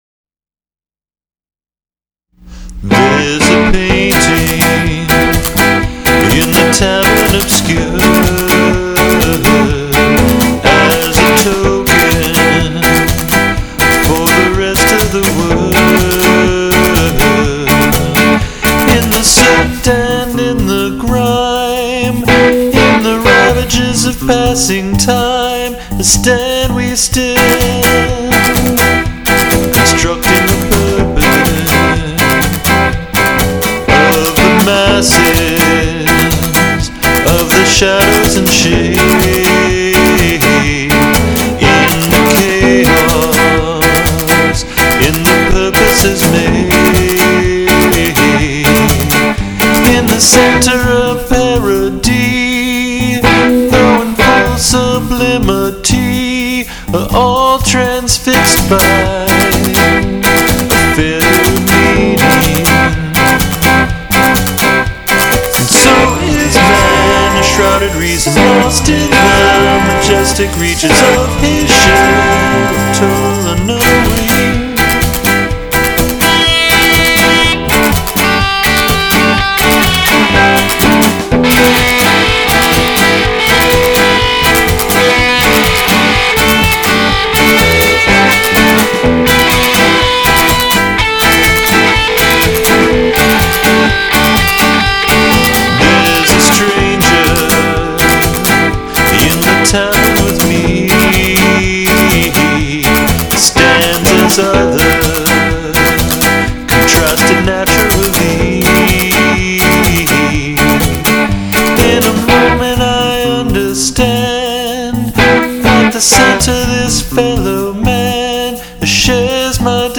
All this talk of other, subject, and object brought me back to high school and college in a big way, and so this song came out a little Smiths-ish, I think.